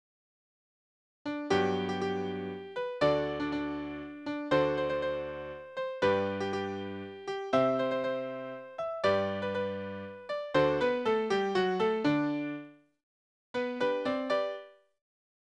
Tonart: G-Dur
Taktart: 3/4, 2/4
Tonumfang: große None
Besetzung: vokal